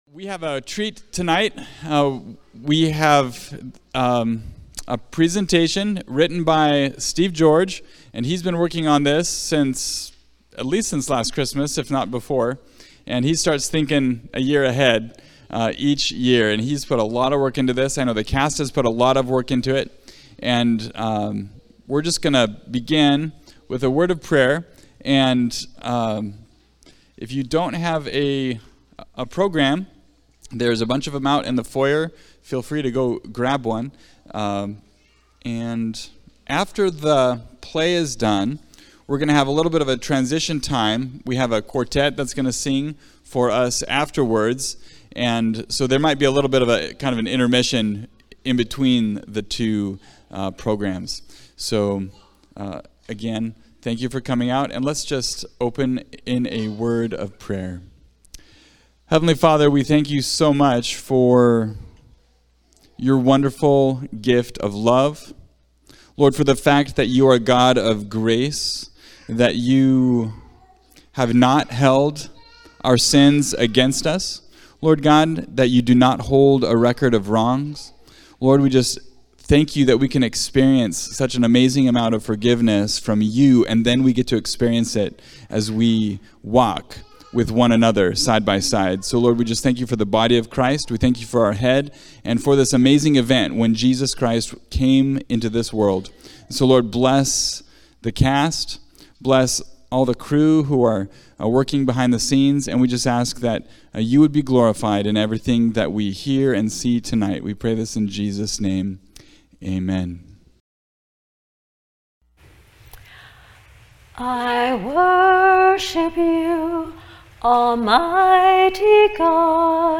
Christmas Program – Play & Quartet (2022)